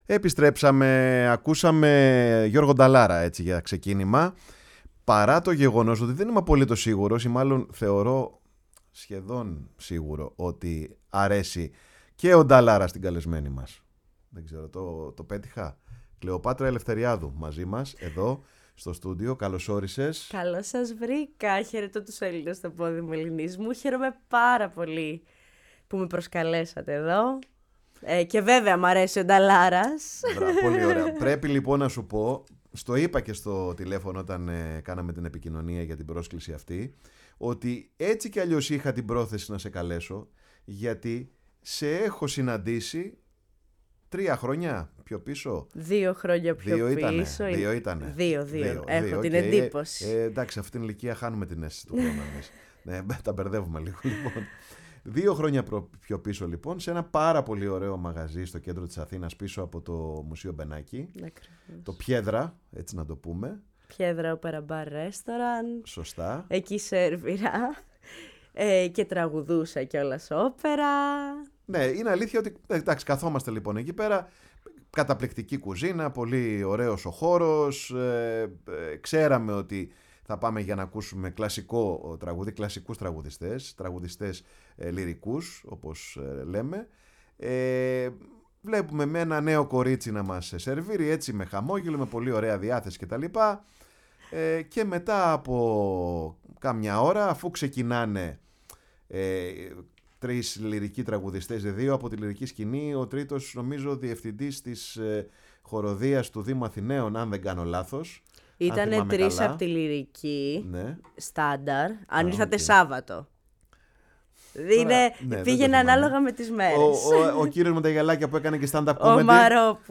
φιλοξένησε στο στούντιο